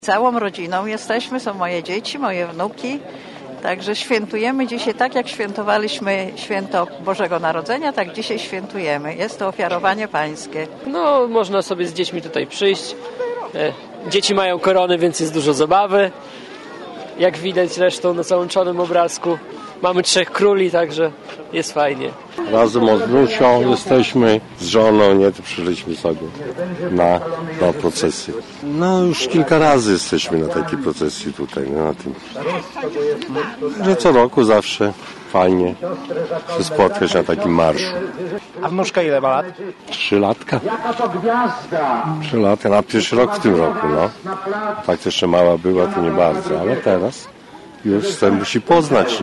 – Orszak Trzech Króli to okazja do integracji międzypokoleniowej i wspólnej manifestacji wiary – przyznawali uczestnicy pochodu.